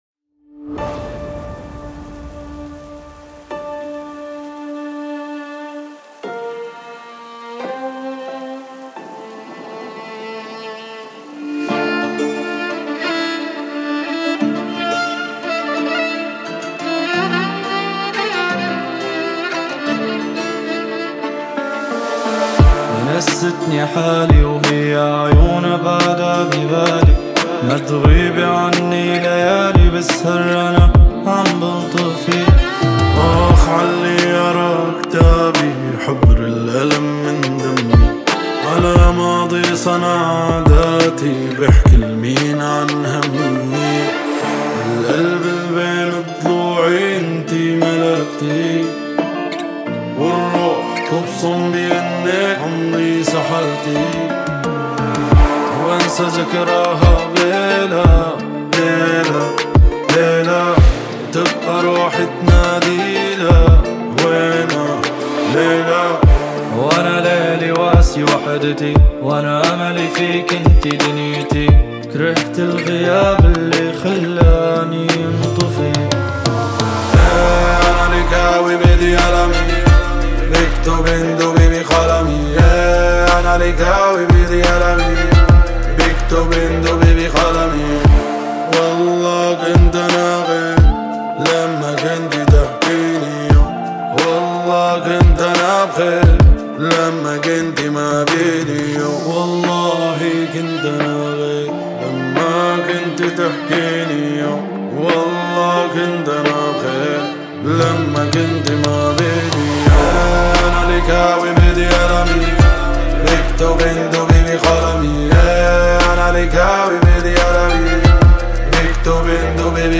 تتميز الأغنية بطابعها الرومانسي والشاعري،